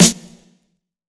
Dilla Snare 08.wav